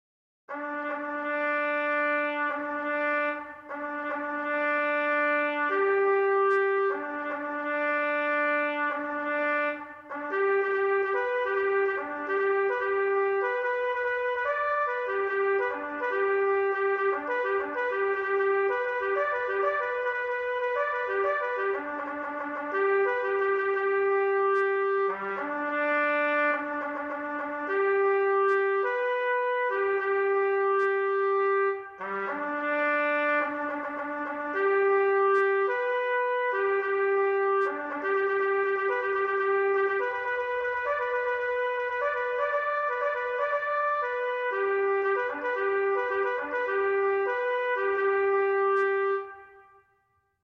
Traditional Bugle Call : Reveille or Long Reveille
This is a bugle call played by military forces to wake up the soldiers at the start of the day.
Long Reveille (bugle call) - MP3 & Midi files